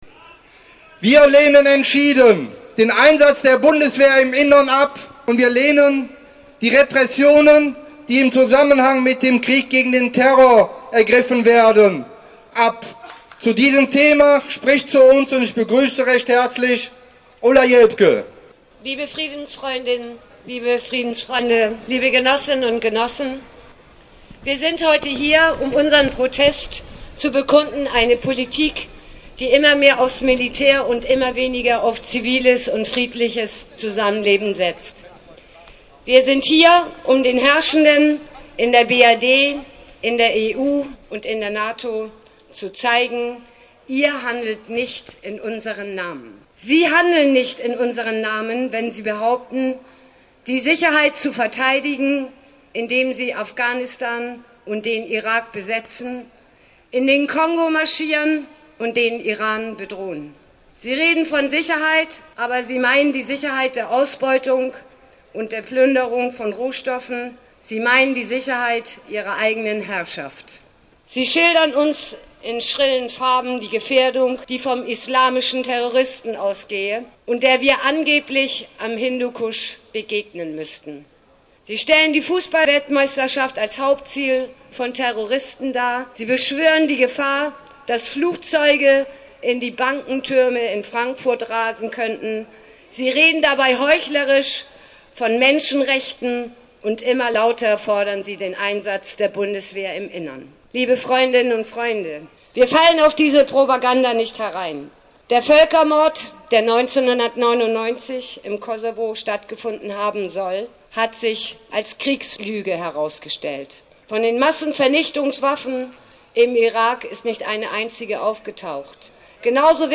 ostermarsch2006_jelpke.mp3